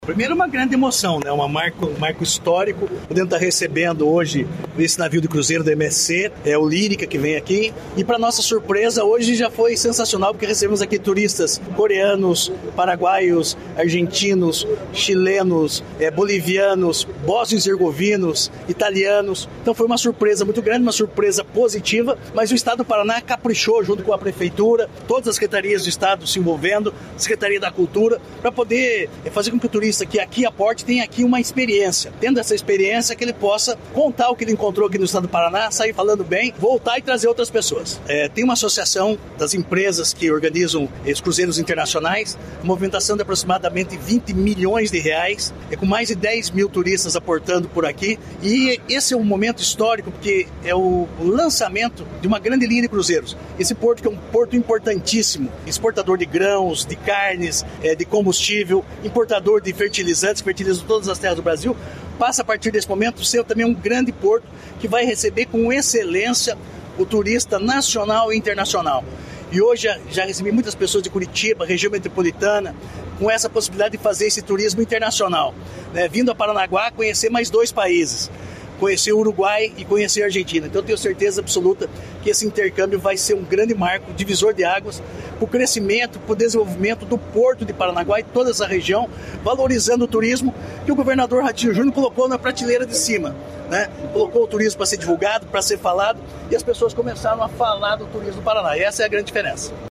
Sonora do secretário do Turismo, Marcio Nunes, sobre o Porto de Paranaguá receber os primeiros navios de cruzeiros da temporada